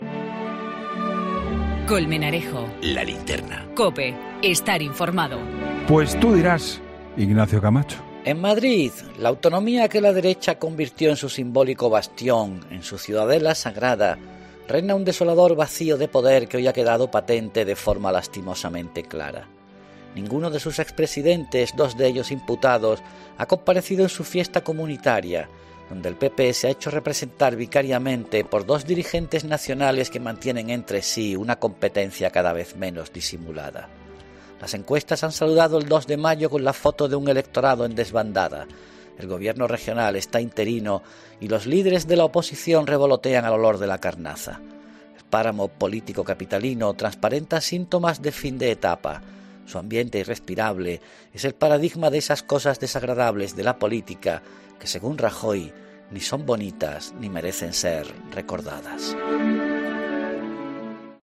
Ignacio Camacho habla en 'La Linterna' de la situación del PP madrileño y su participación en la festividad del dos de mayo tras la dimisión de la presidenta Cristina Cifuentes